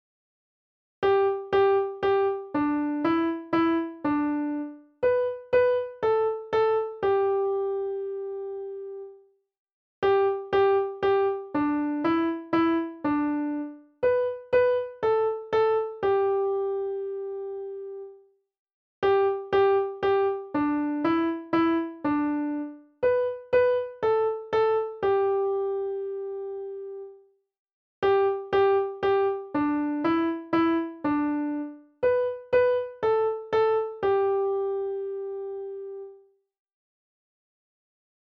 On the piano, play Old Macdonald Had A Farm
G G G D E E D
B B A A G